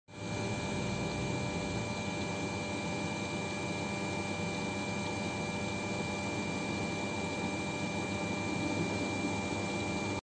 Grésillement unité exterieure climatiseur Mitsubishi
Après la mise en route l'unité extérieure émet un grésillement d'ordre électrique uniquement lorsqu'elle est en froid.
Après avoir écouté plusieurs fois votre enregistrement, j’entends que le bruit du compresseur (fonctionnement Inverter).